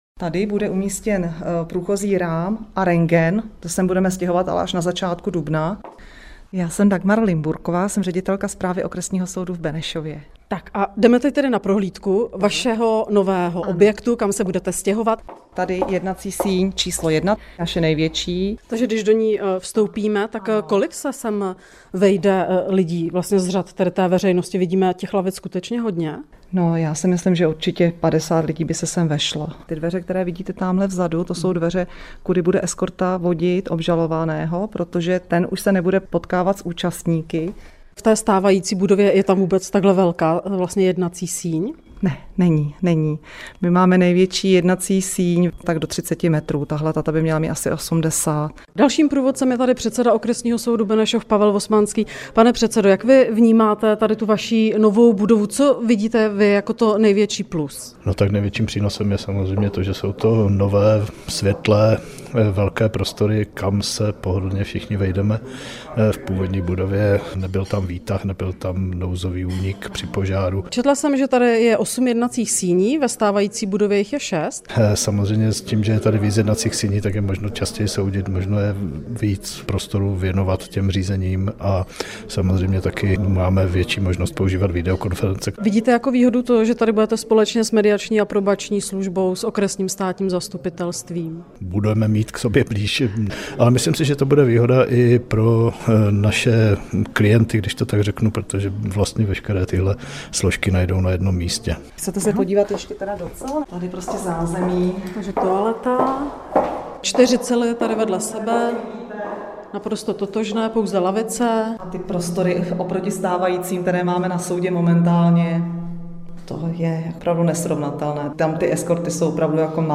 Zprávy Českého rozhlasu Střední Čechy: Kompletní rekonstrukce pražského Průmyslového paláce skončí příští rok v červnu.